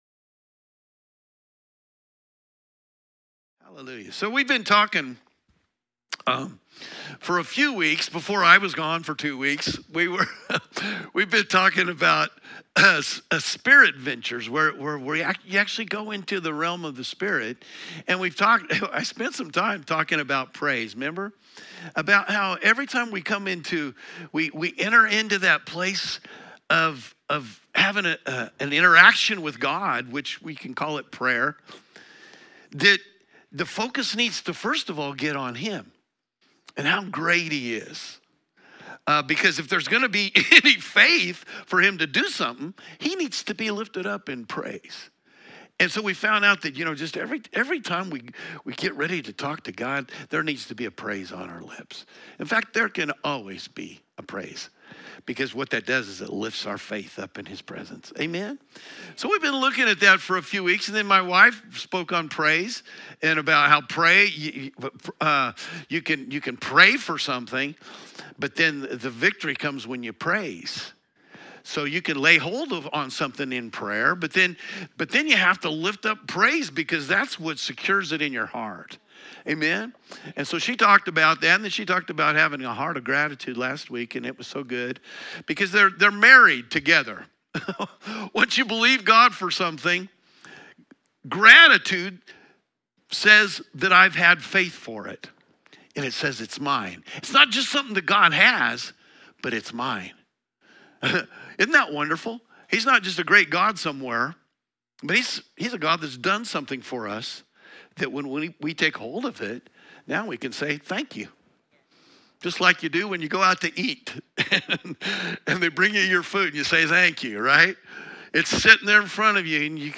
Sermons | New Life Church LH